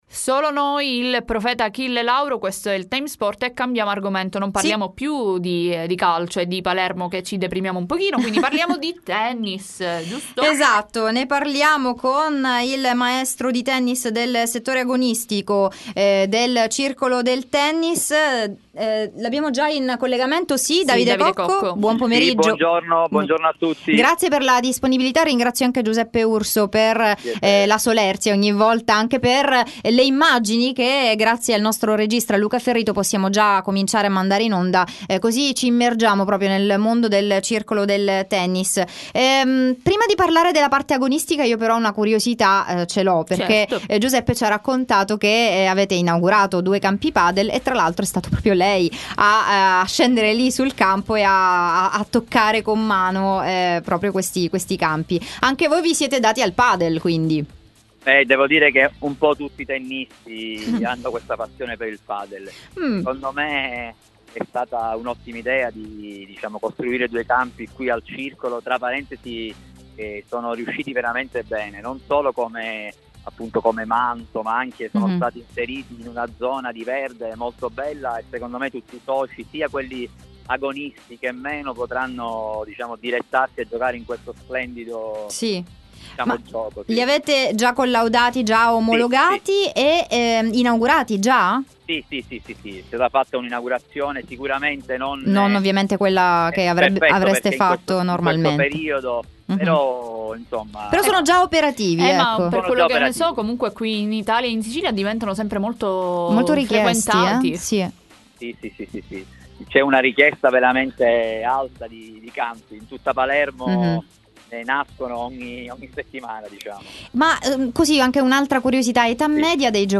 T.S. Intervista